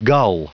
Prononciation du mot gull en anglais (fichier audio)
Prononciation du mot : gull